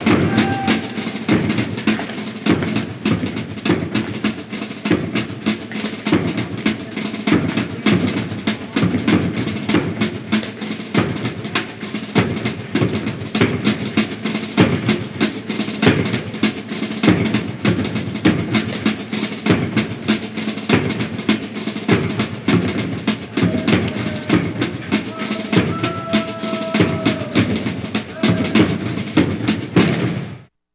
Drum Corps | Sneak On The Lot
Military Drum Corps Battle March With Vocals